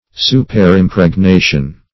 Search Result for " superimpregnation" : The Collaborative International Dictionary of English v.0.48: Superimpregnation \Su`per*im`preg*na"tion\, n. The act of impregnating, or the state of being impregnated, in addition to a prior impregnation; superfetation.